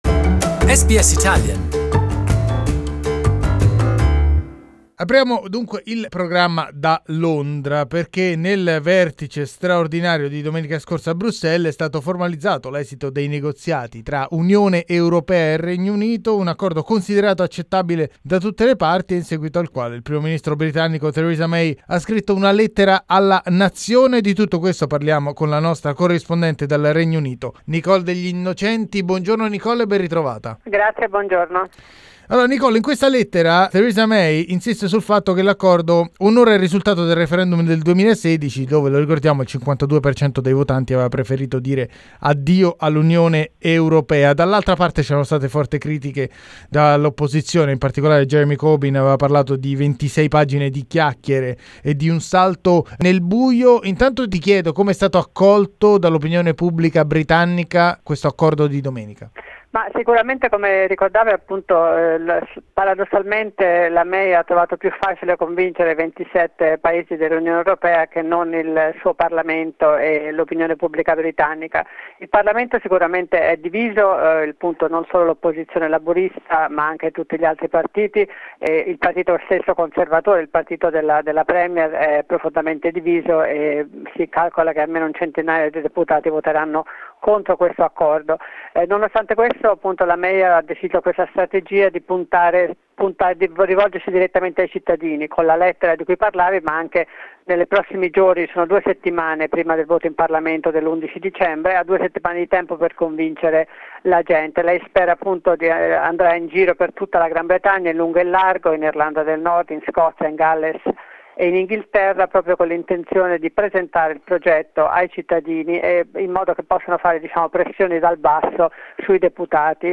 The Brexit process has moved to its next stage after European Union leaders approved the divorce deal, it is now up to the British parliament to approve it. Journalist